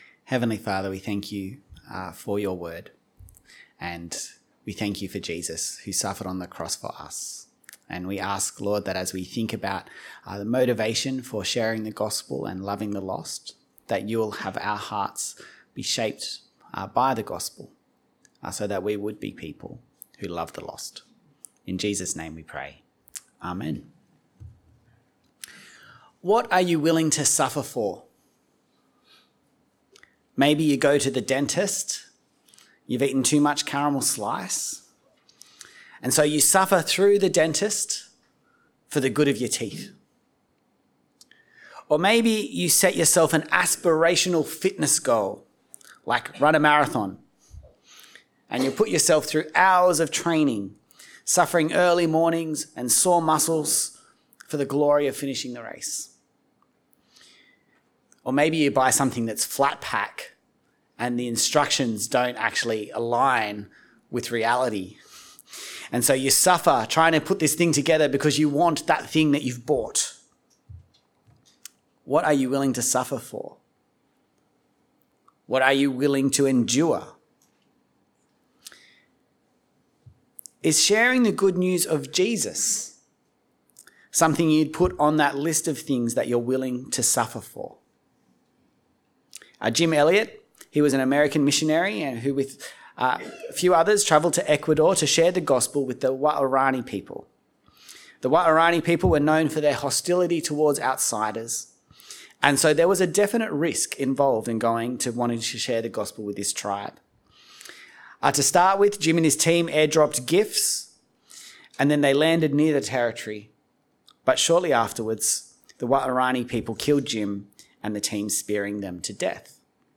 2 Timothy 2 - For the sake of the lost - sermon.mp3